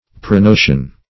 Search Result for " prenotion" : The Collaborative International Dictionary of English v.0.48: Prenotion \Pre*no"tion\, n. [L. praenotio: cf. F. pr['e]notion.